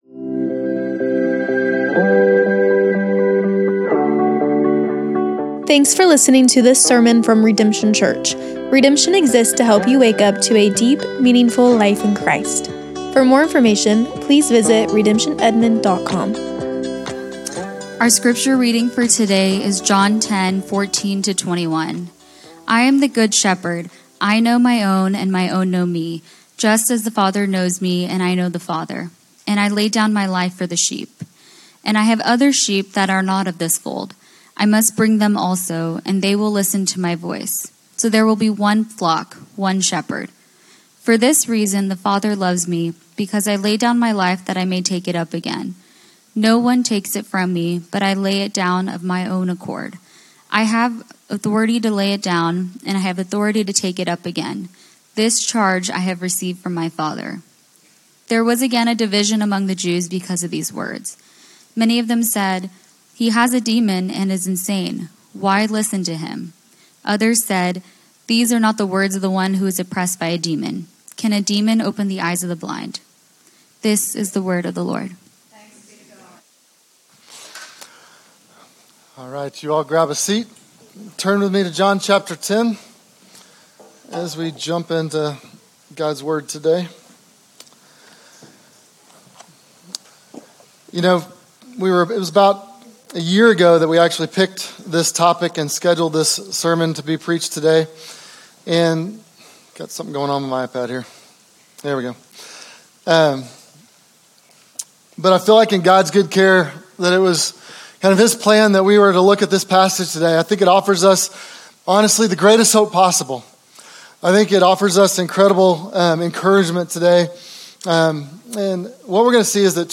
SERMONS - Redemption Church